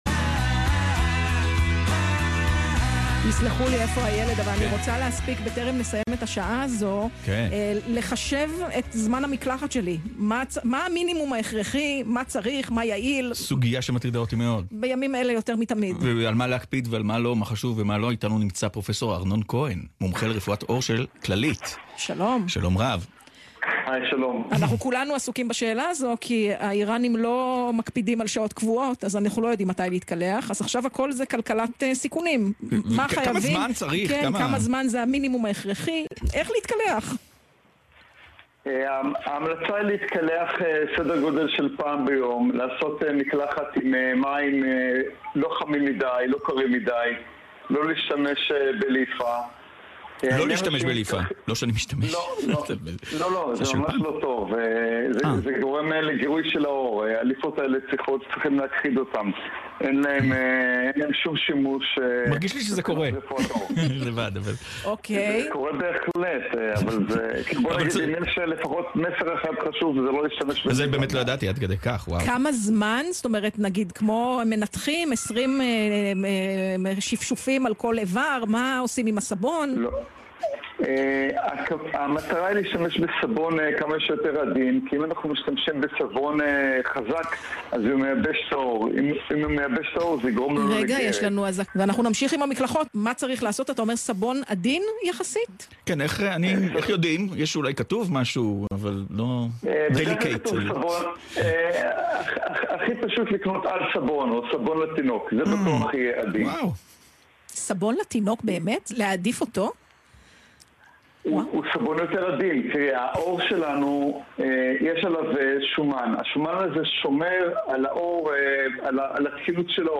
מראיין אחד מציין כי הוא נהנה לחפוף מדי יום בגלל ריח השמפו, בעוד שמראיינת אחרת משתפת שהיא נמנעת מכך עקב המאמץ הכרוך בייבוש ובעיצוב השיער ("הפריזורה")
ראיון-על-מקלחות.mp3